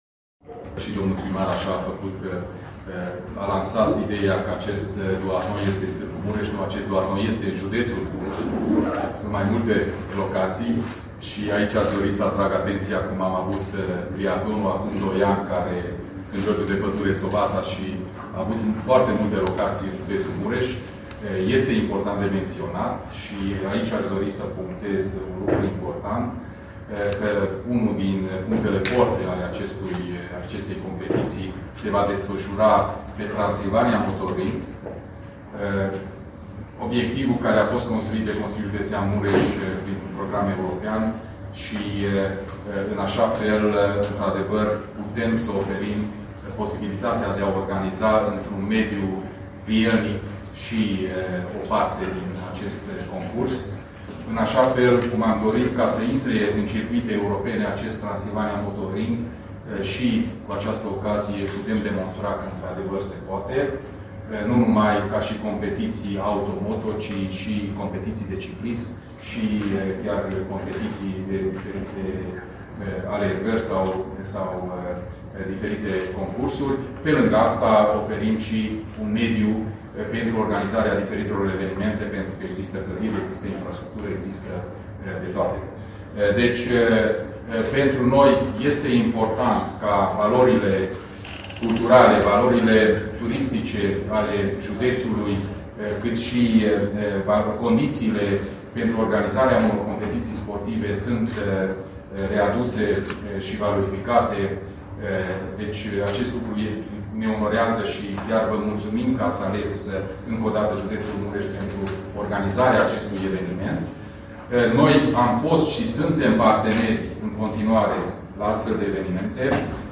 La conferința de presă organizată joi, 1 iulie, la Hotel Grand din Tg.-Mureș, cu prilejul Campionatelor Europene de duathlon